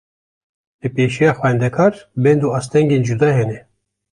Uitgesproken als (IPA)
/xʷɛndɛˈkɑːɾ/